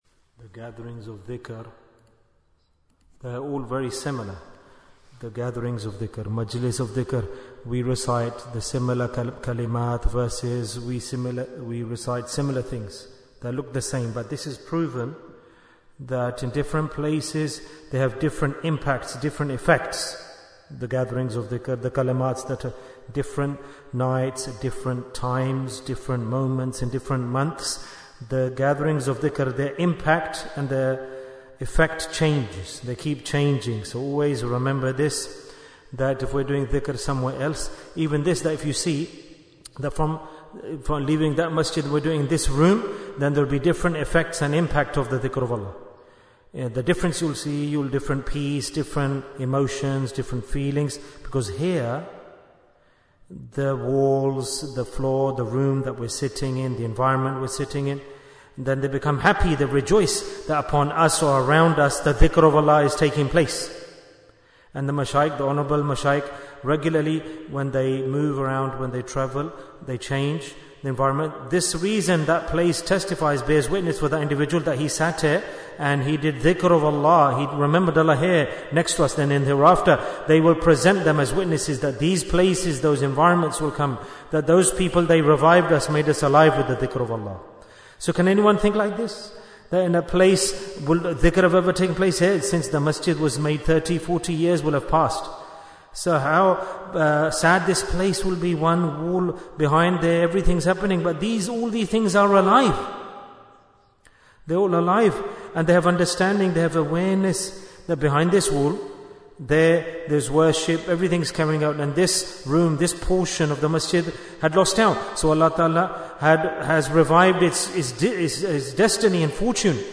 Talk before Dhikr 1812 minutes10th February, 2025